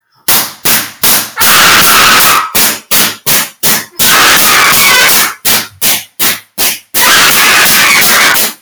Clapping Bass Boosted